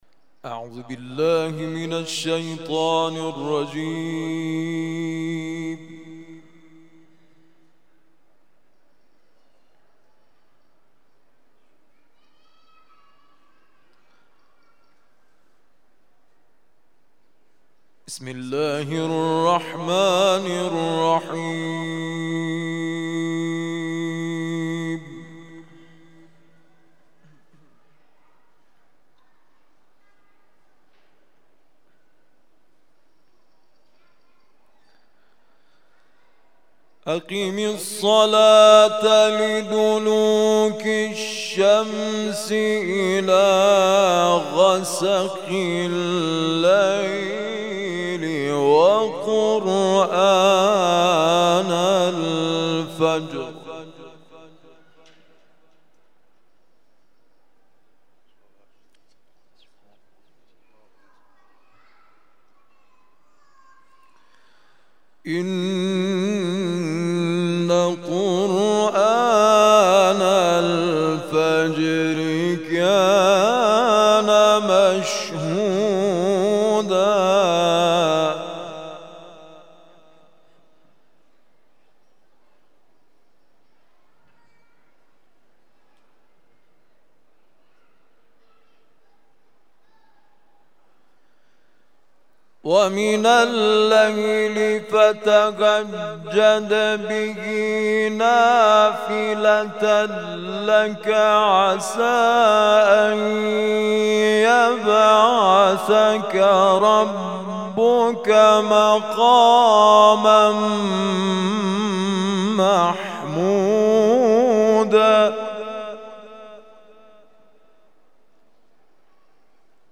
تلاوت مغرب
تلاوت قرآن کریم